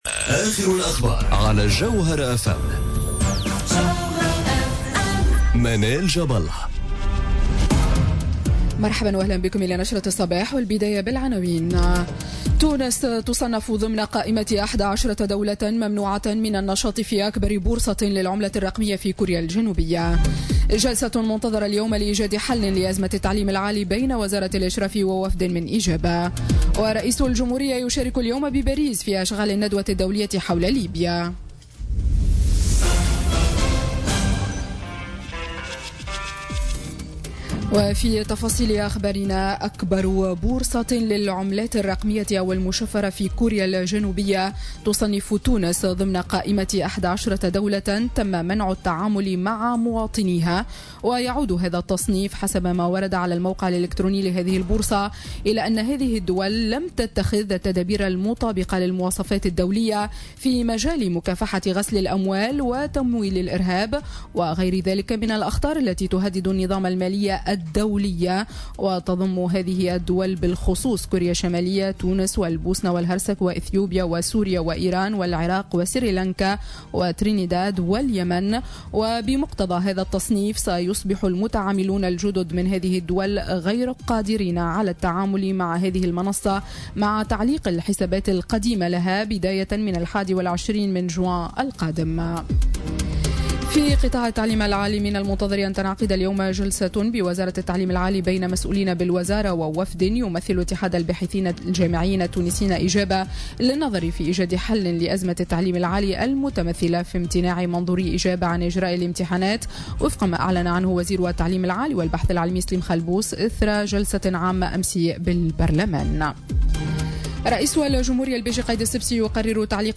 نشرة أخبار السابعة صباحا ليوم الثلاثاء 29 ماي 2018